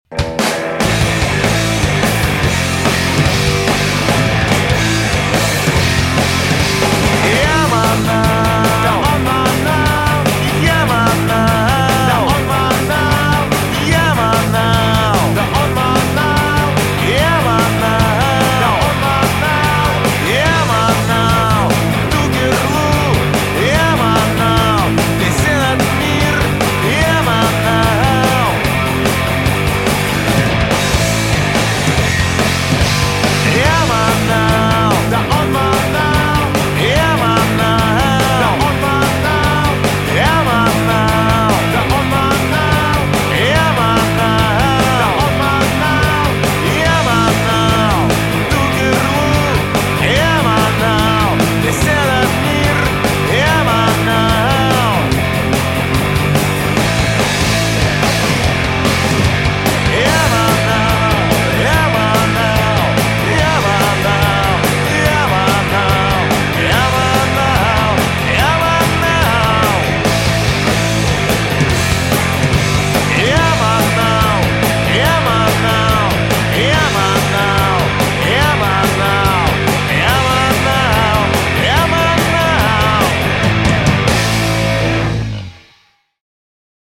кавер на песню